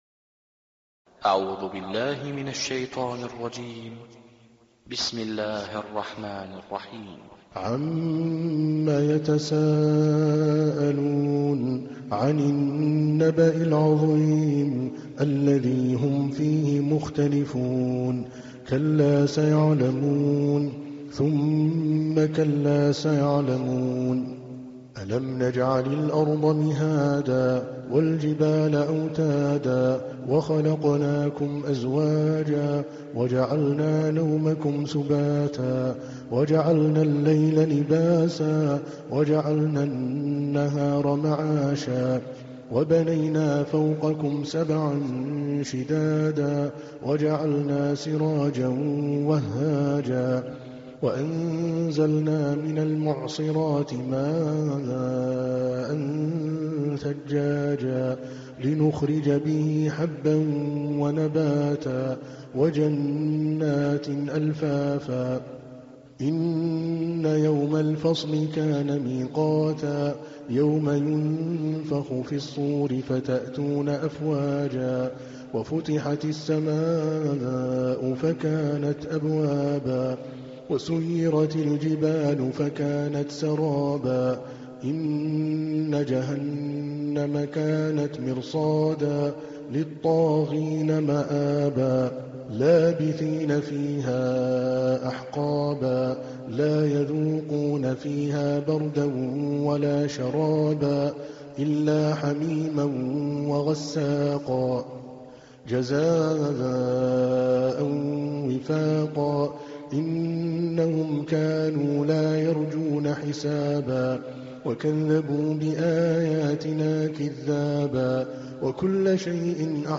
78. Surah An-Naba' سورة النبأ Audio Quran Tarteel Recitation
Surah Sequence تتابع السورة Download Surah حمّل السورة Reciting Murattalah Audio for 78.